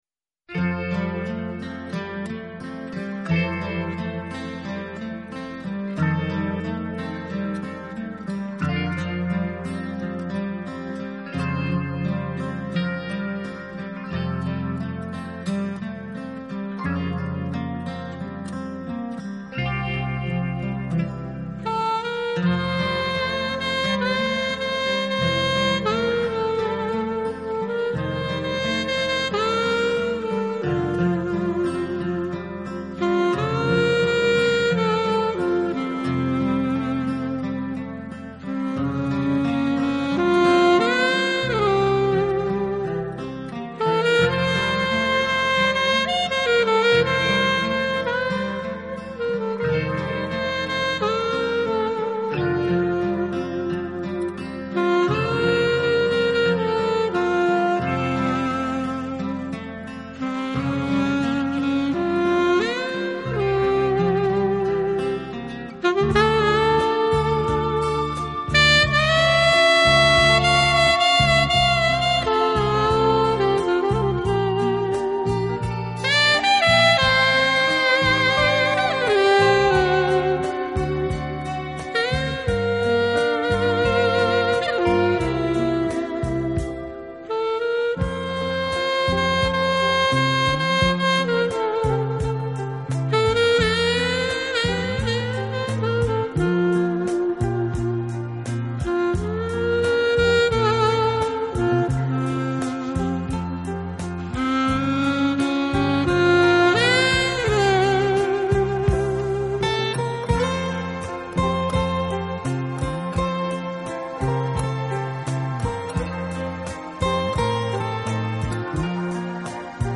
曲风在令人陶醉的浪漫情怀中，不经意的透露出一丝清新淡雅。